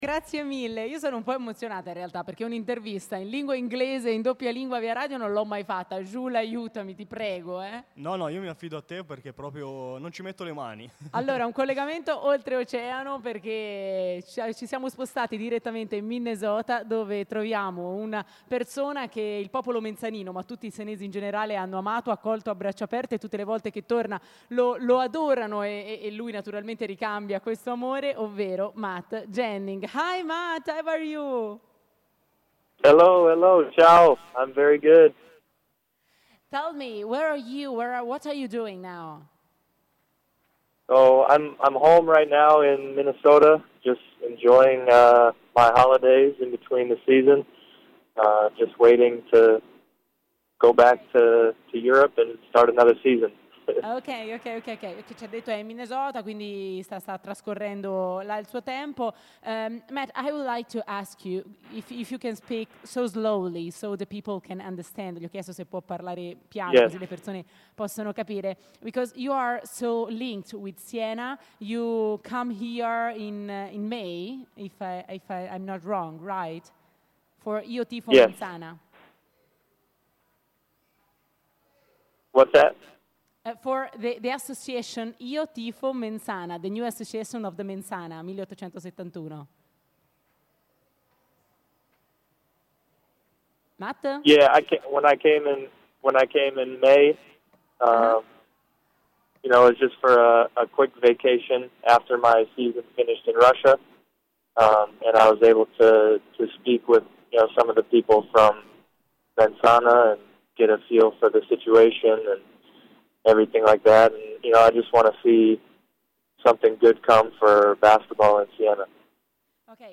"Un Palio da pazzi", l'intervista all'ex mensanino Matt Janning - Antenna Radio Esse